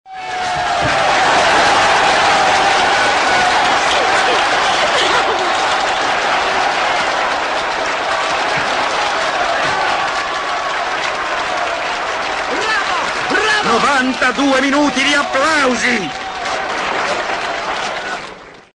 Download Applausi! sound button
applausi.mp3